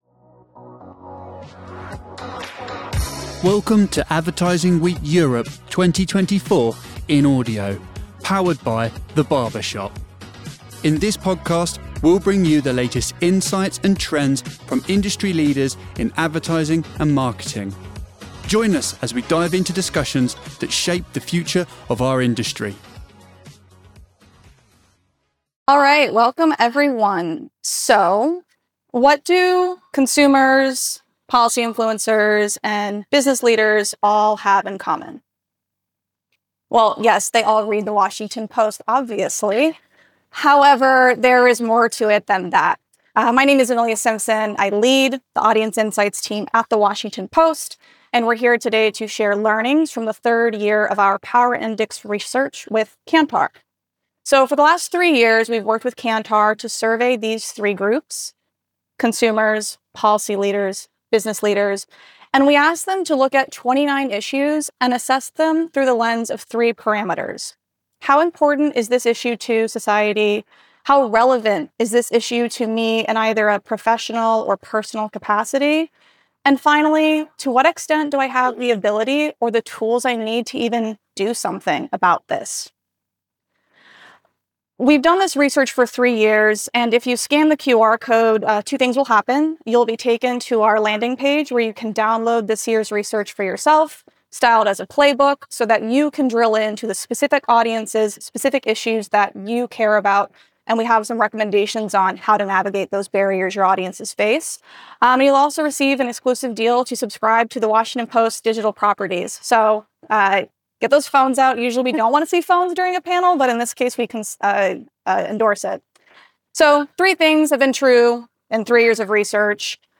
Advertising Week Europe 2024 in Audio